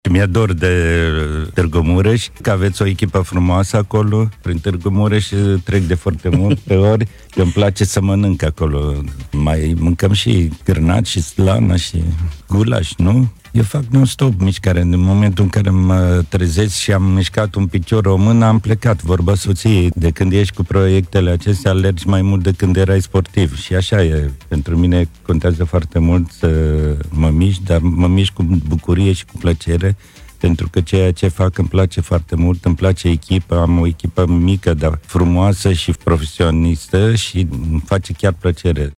Marele sportiv român a mărturisit într-una din edițiile emisiunii ”De 10 ori România” transmisă pe rețeaua Radio România Regional că este îndrăgostit de Delta Dunării, unde se simte mereu ca acasă, dar iubește și mun. Târgu Mureș, mai ales bucătăria de aici: